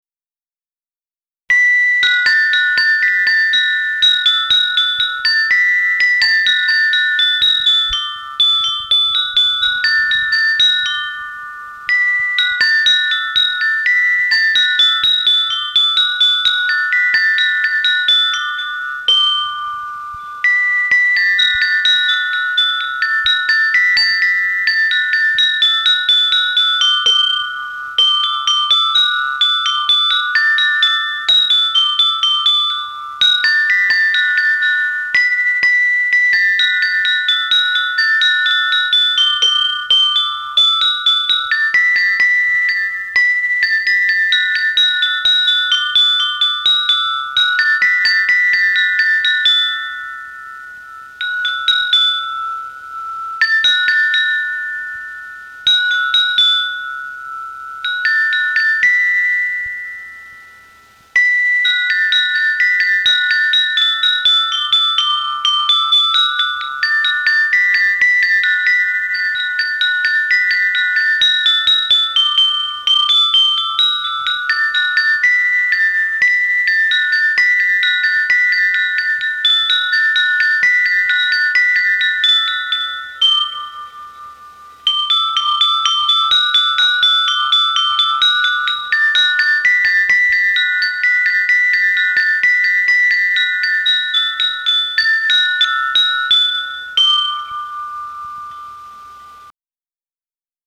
Tags: original instrumental guitar keyboard